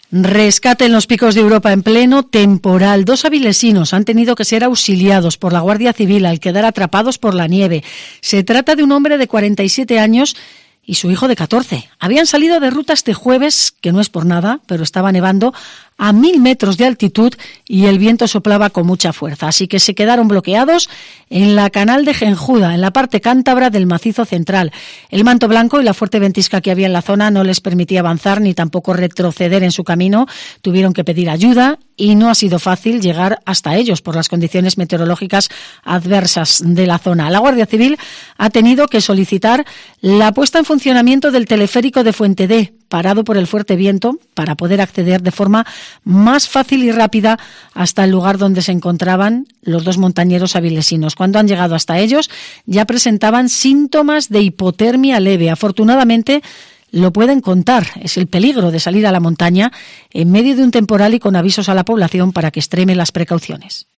Así hemos contado en Herrera en COPE el rescate en la nieve de dos avilesinos